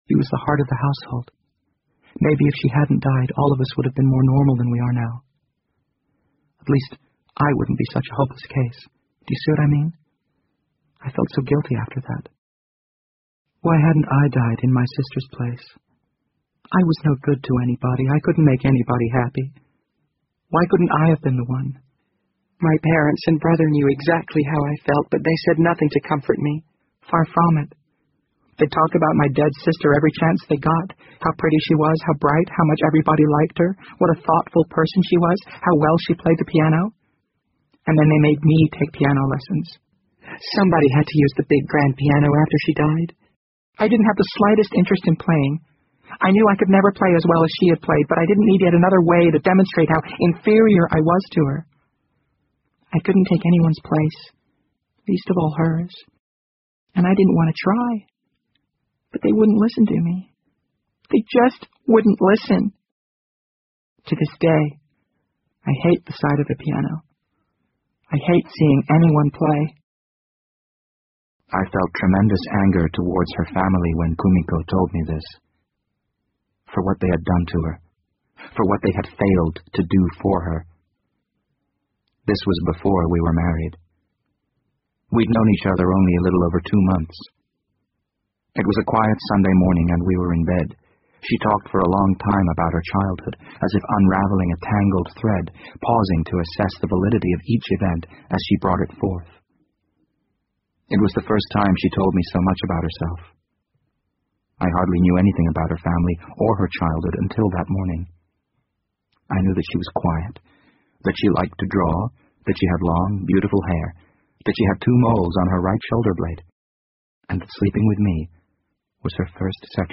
BBC英文广播剧在线听 The Wind Up Bird 35 听力文件下载—在线英语听力室